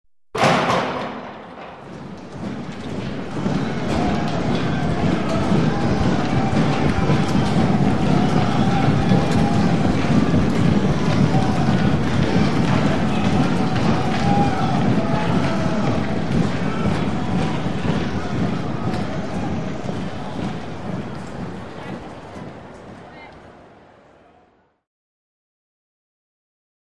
Players, Push Bar Door Open, Walla, Foot Steps By In Tunnel. Could Use For Various Sports. Room Reverb.